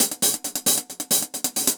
UHH_AcoustiHatC_135-01.wav